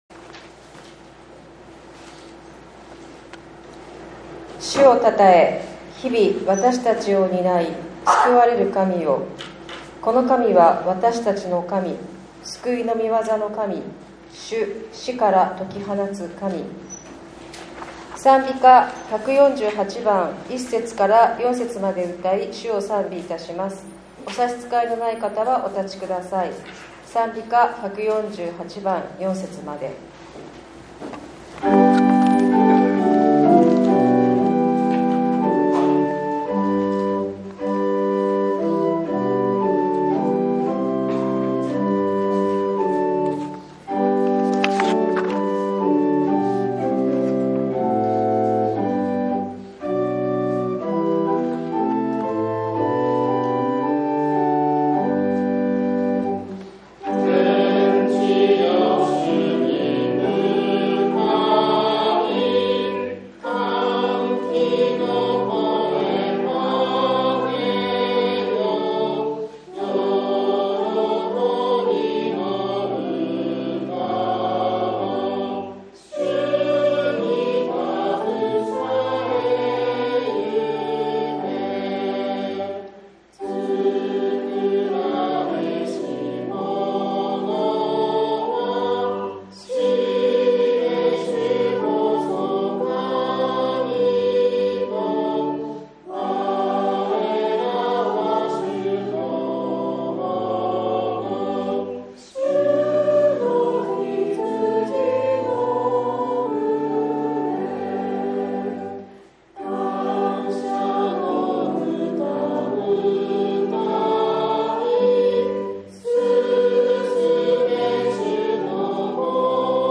５月２５日（日）主日礼拝